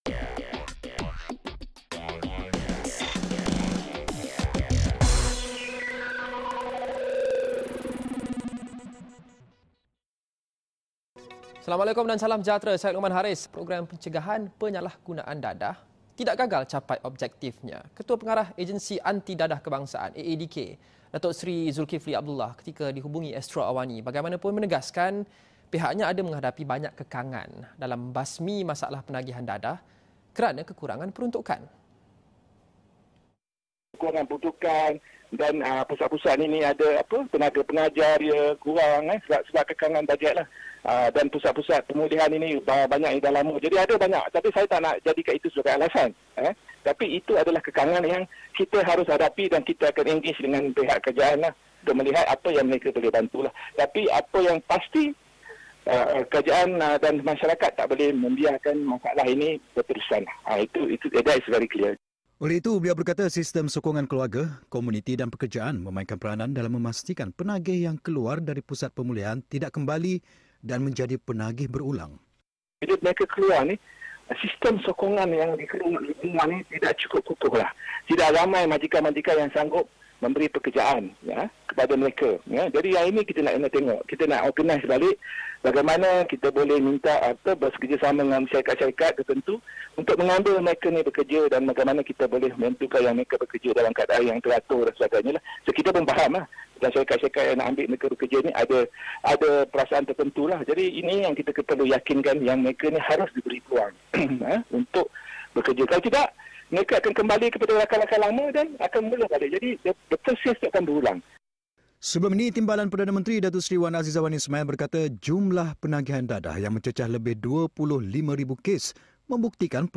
Program pencegahan penyalahgunaan dadah tidak gagal capai objektifnya. Ketua Pengarah Agensi Anti Dadah Kebangsaan (AADK), Datuk Seri Zulkifli Abdullah, ketika dihubungi Astro AWANI bagaimanapun menegaskan pihaknya menghadapi banyak kekangan dalam membasmi masalah penagihan dadah kerana kekurangan peruntukan.